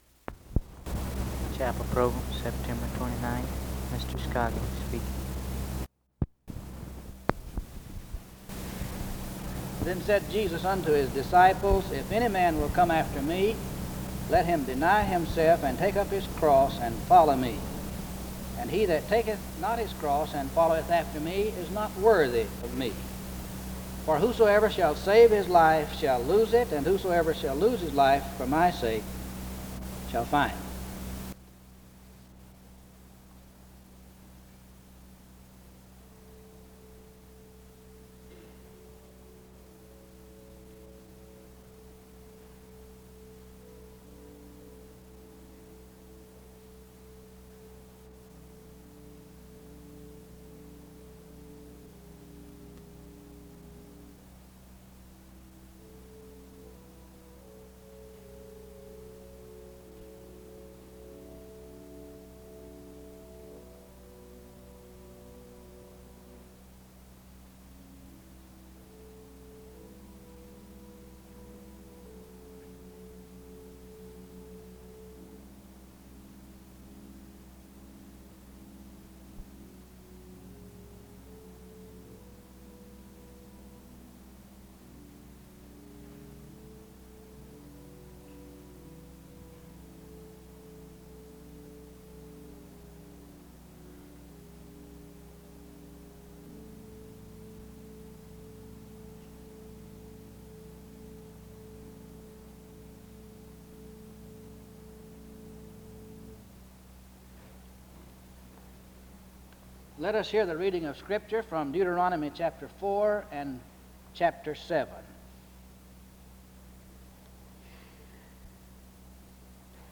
The service begins with a scripture reading and music from 0:00-1:45.
Various verses from Deuteronomy 4 and 7 are read from 1:50-5:09. A hymn is sung from 5:11-8:58. A prayer is offered from 9:05-11:49. Music plays from 11:57-13:39.